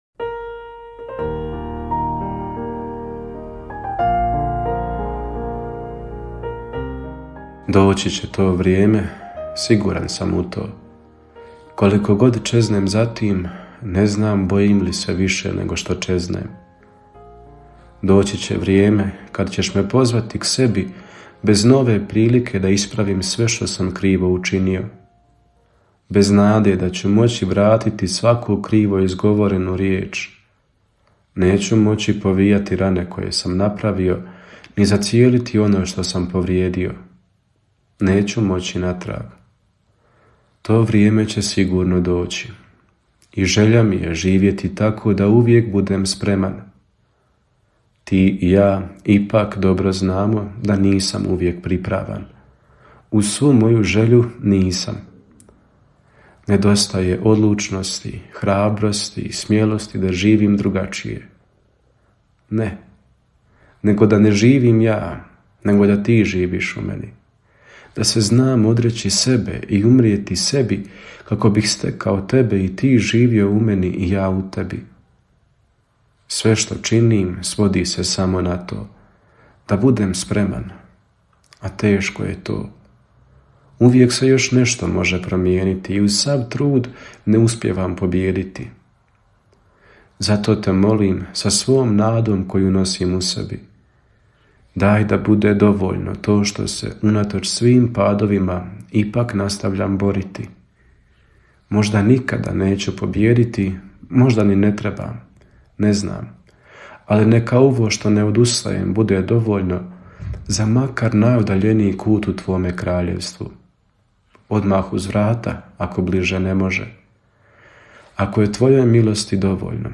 Kratku emisiju ‘Duhovni poticaj – Živo vrelo’ slušatelji Radiopostaje Mir Međugorje mogu slušati od ponedjeljka do subote u 3 sata, te u 7:10.